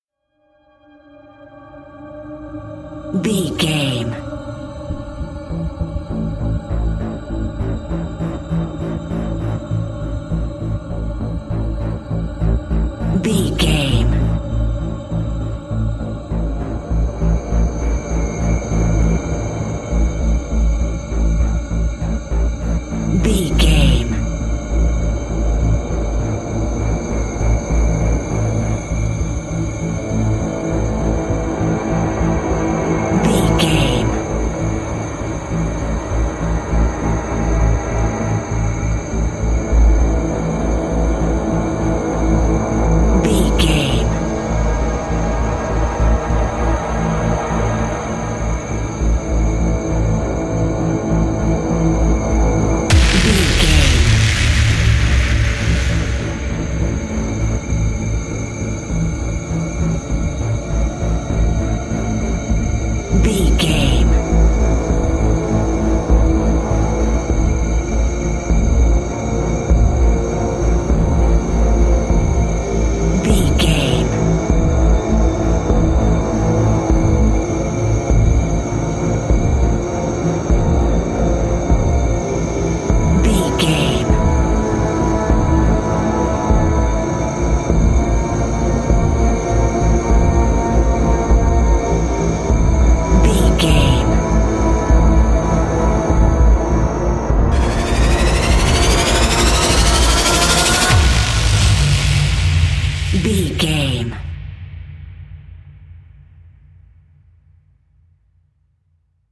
Survival horror
Thriller
Aeolian/Minor
synthesiser
percussion